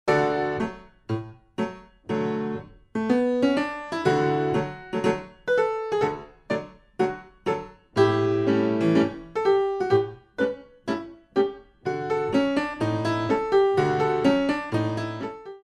Classical Arrangements for Pre Ballet Classes